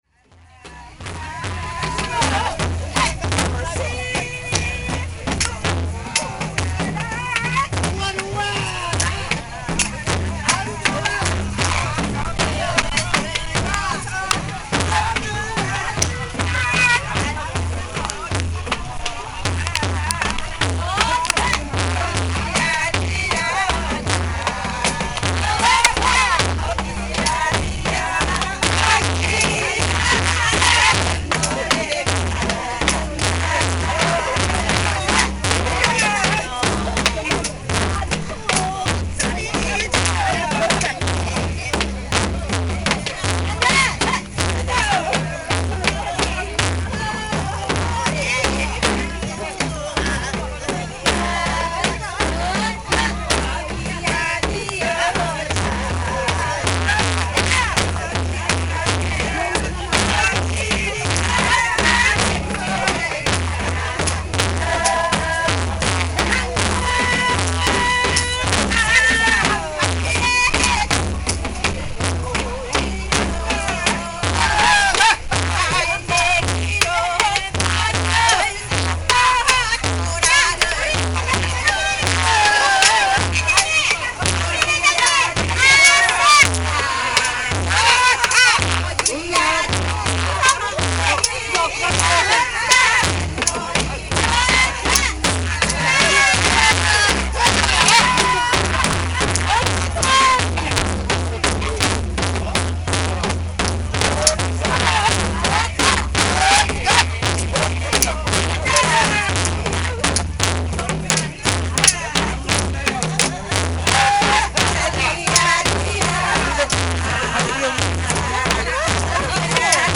Tags: South Korea adjumma traditional